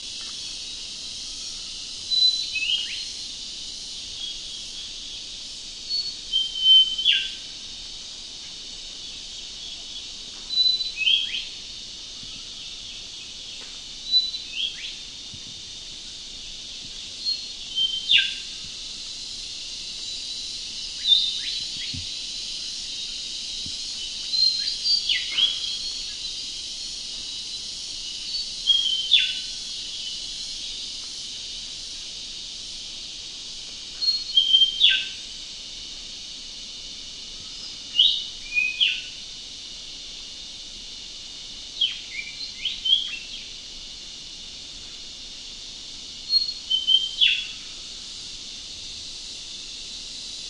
蝉 (菲律宾巴拉望岛)
描述：在晚上（下午6点）处理热带蝉的音频。在音频处理阶段（约2.3千赫兹和波纹管被移除），一些背景声音滑落并且无法移除。这些热带蝉在晚上活跃。这些蝉能够产生高于2.5千赫兹的呼叫。这个音频是从源头2550米左右录制的。
标签： 森林 巴拉望岛 热带 中投公司ADAS 菲律宾 昆虫 性质 现场记录
声道立体声